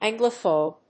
音節An・glo・phobe 発音記号・読み方
/ˈæŋgləfòʊb(米国英語)/